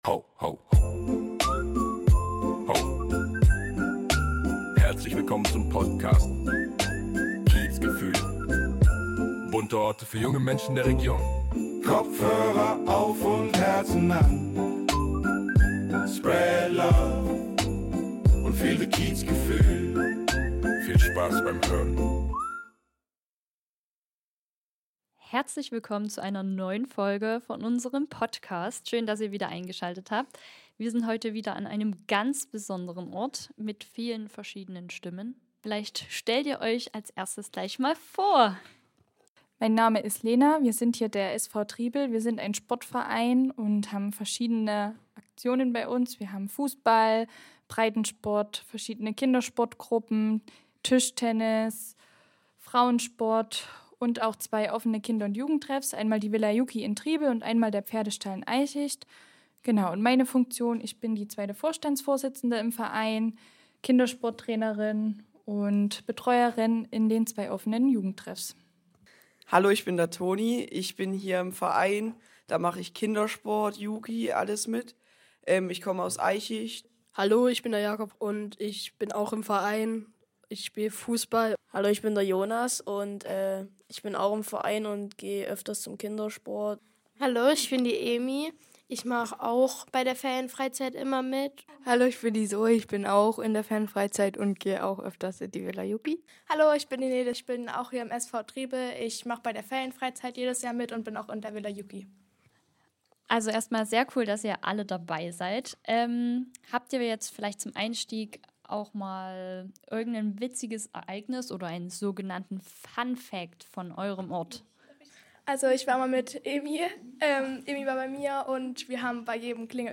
Heute hören wir viele verschiedene Stimmen von Jugendlichen aus Triebel